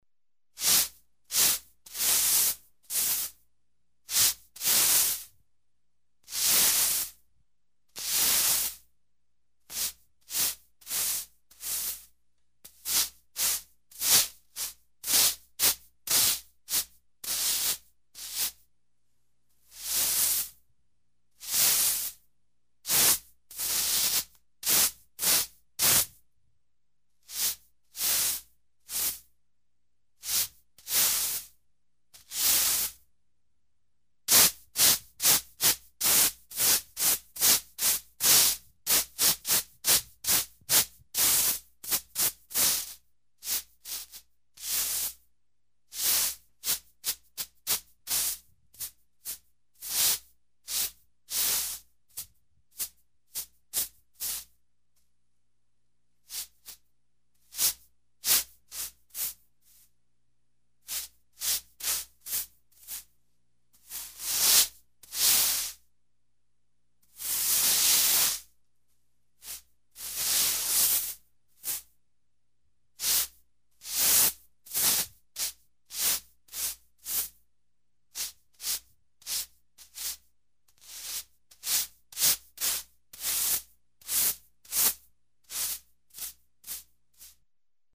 Звуки метлы: шуршание метлы по выпавшему снегу